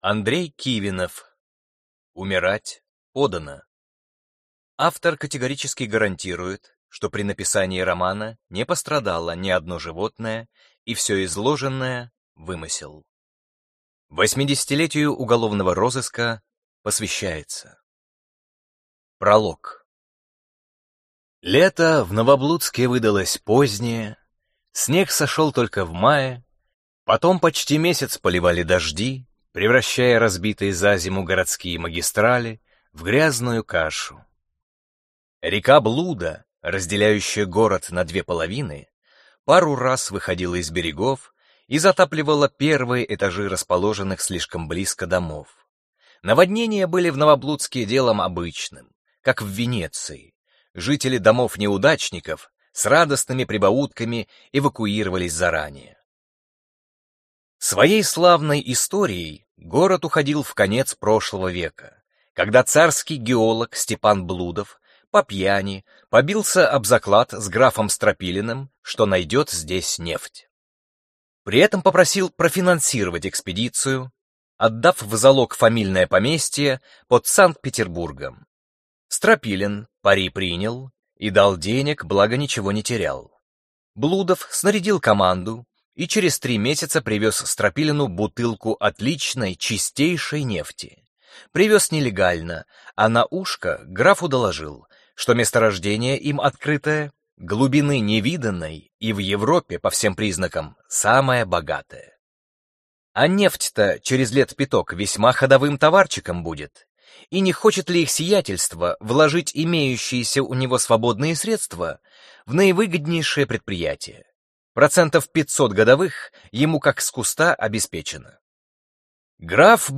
Аудиокнига Умирать подано | Библиотека аудиокниг